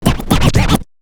Scratch 12.wav